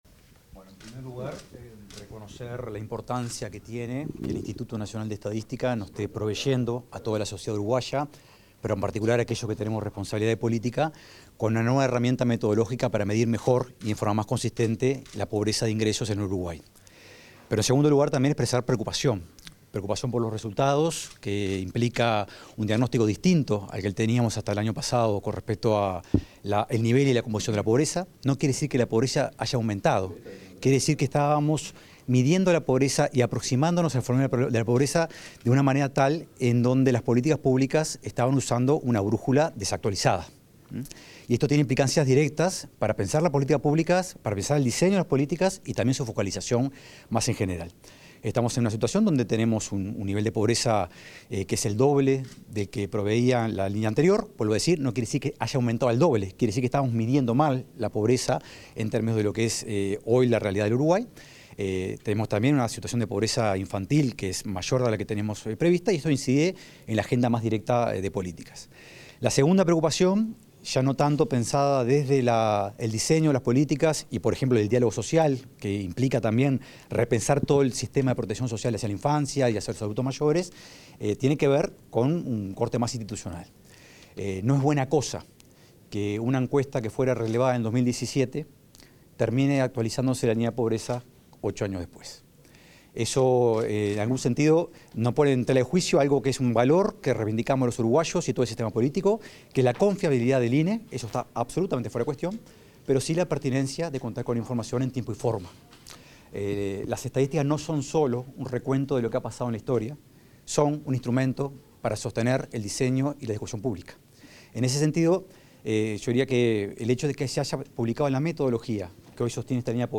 Declaraciones del director de OPP, Rodrigo Arim
El director de la Oficina de Planeamiento y Presupuesto (OPP), Rodrigo Arim, brindó declaraciones a la prensa luego de la presentación de los datos